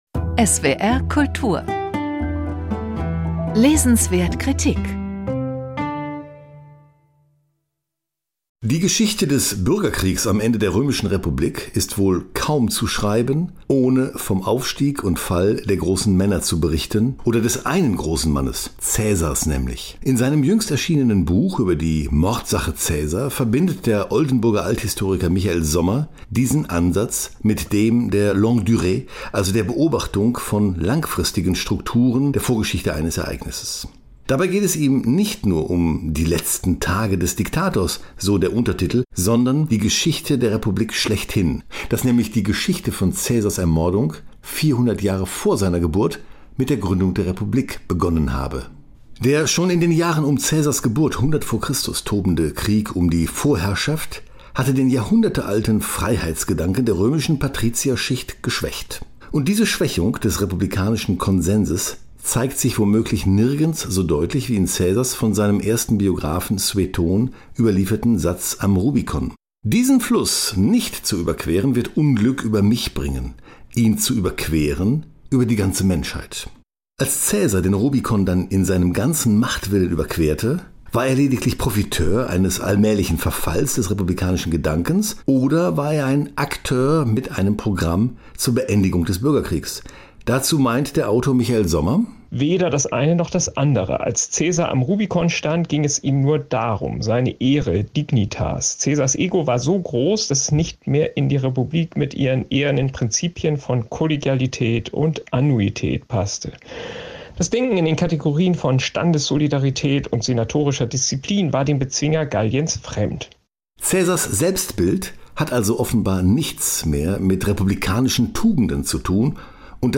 Michael Sommer – Mordsache Caesar | Buchkritik 4:09